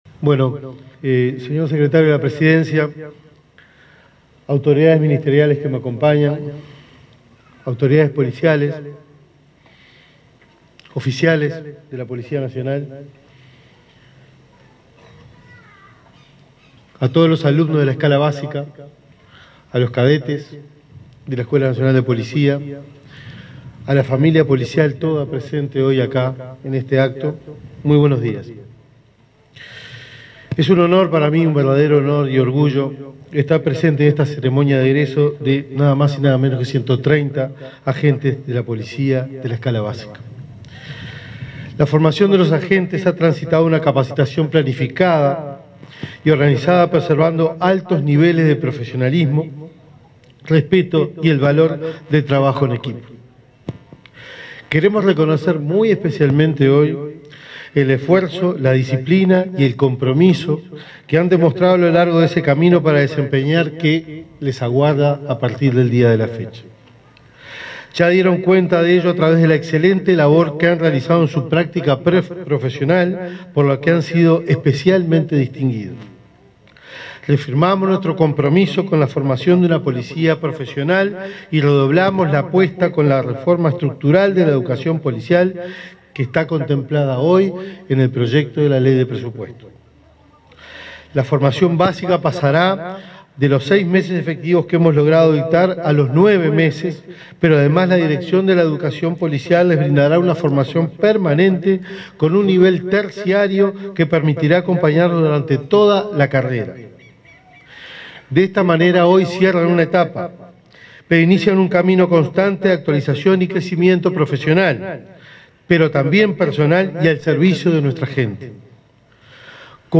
Palabras del ministro del Interior, Carlos Negro
En la ceremonia de egreso de 130 cadetes de la Promoción LXXII de la Escuela Policial de la Escala Básica, denominada Honor, Disciplina y Denuedo, se